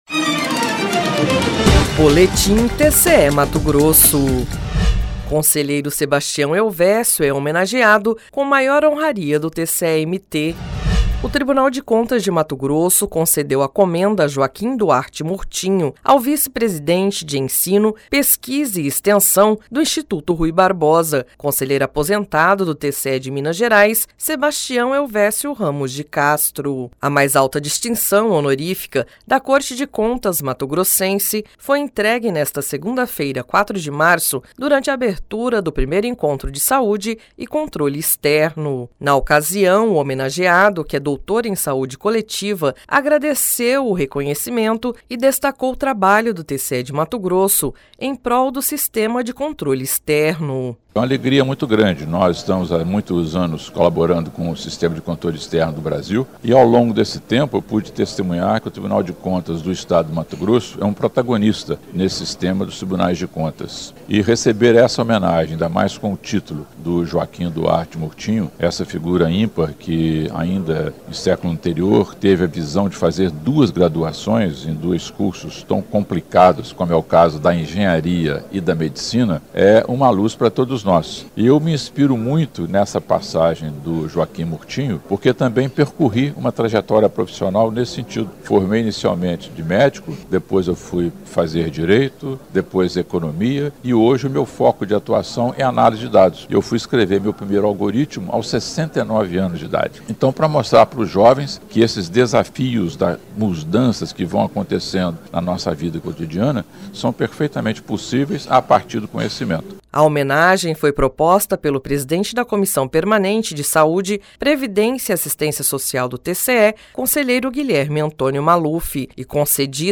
Sonora: Sebastião Helvécio Ramos de Castro - conselheiro aposentado do TCE de Minas Gerais